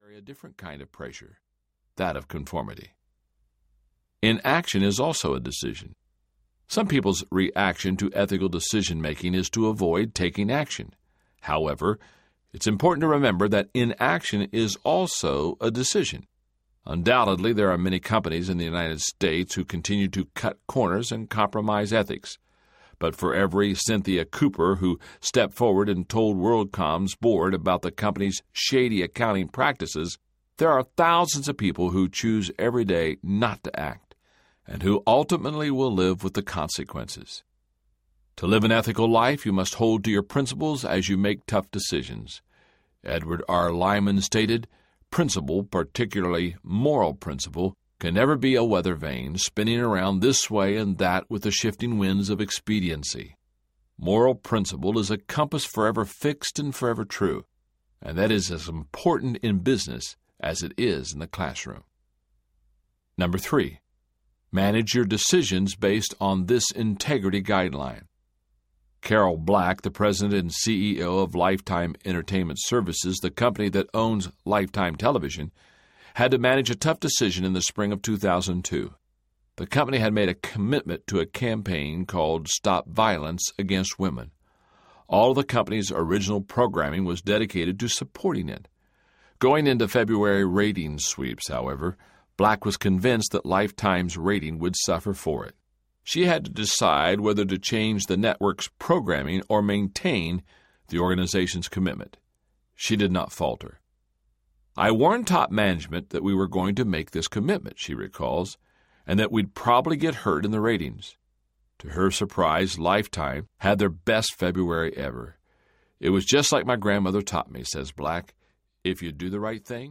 There’s No Such Thing As Business Ethics Audiobook
2.4 Hrs. – Unabridged